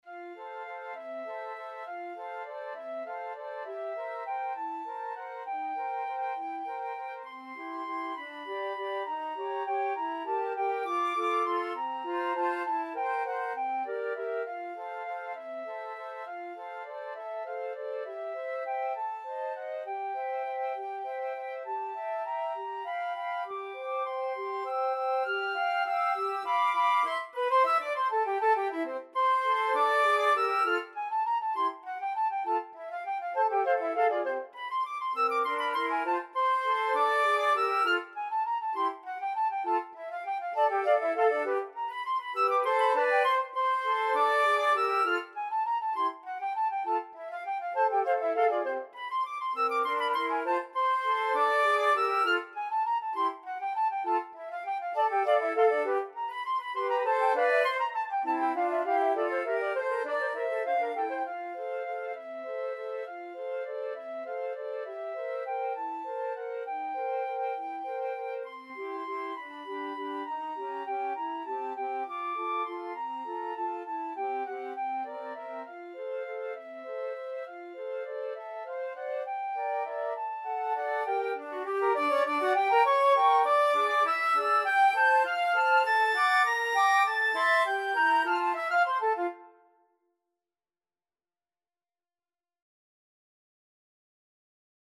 Free Sheet music for Flute Trio
F major (Sounding Pitch) (View more F major Music for Flute Trio )
Classical (View more Classical Flute Trio Music)